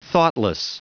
Prononciation du mot : thoughtless